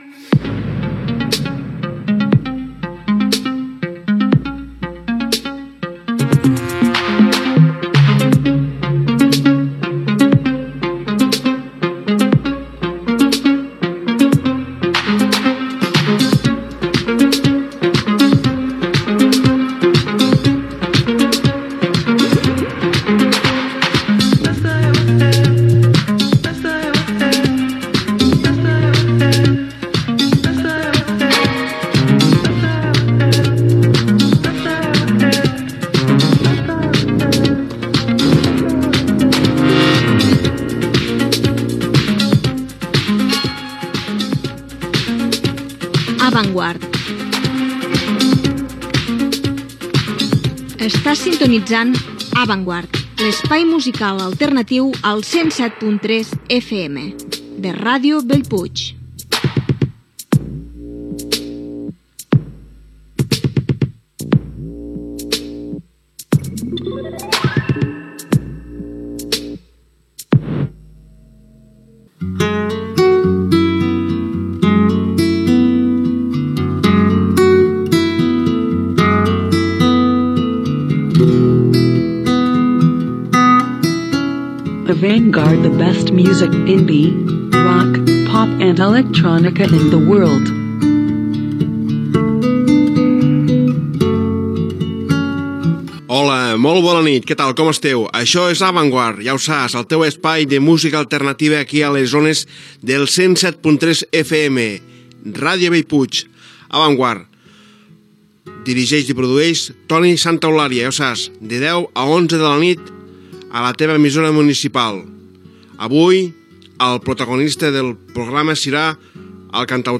Musical
FM